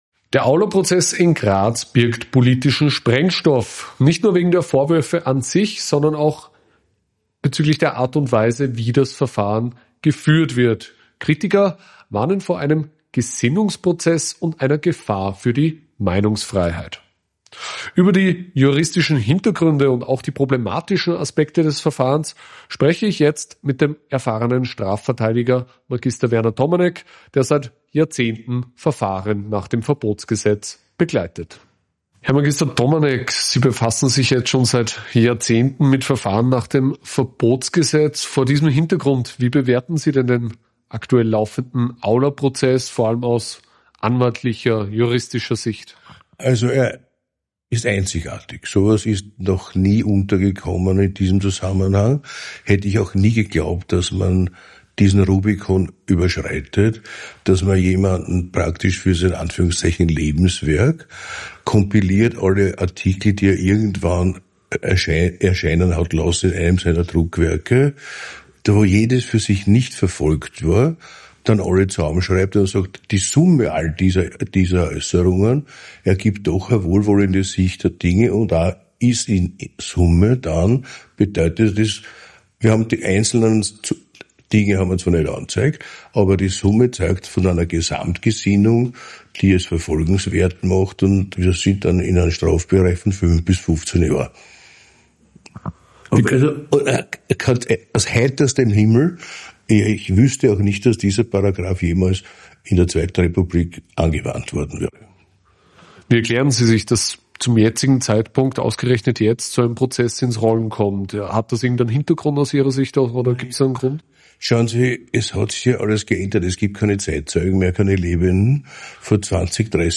Im AUF1-Interview spricht er von einem „überschrittenen Rubikon“: Artikel, die einzeln nie beanstandet wurden, sollen nun im Gesamtbild als Ausdruck einer strafbaren Gesinnung gewertet werden.